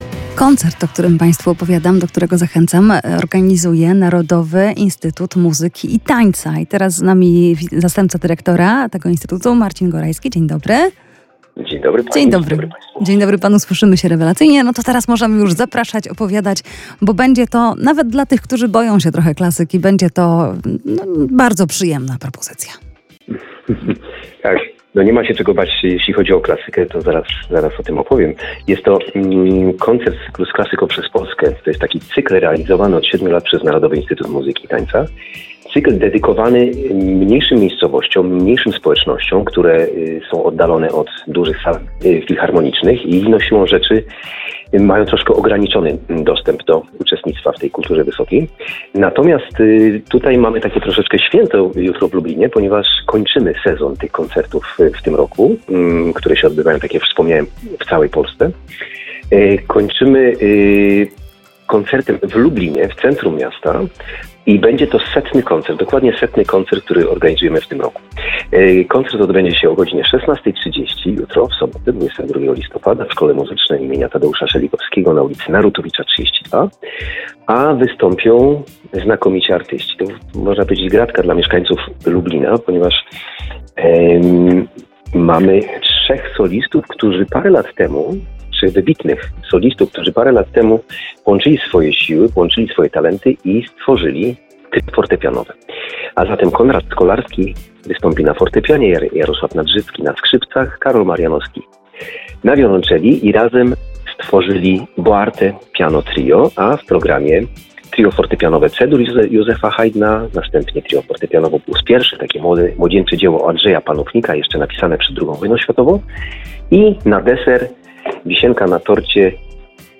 Radio Lublin > Redakcje > Muzyka > Boarte Piano Trio – wyjątkowy koncert w Lublinie [POSŁUCHAJ ROZMOWY]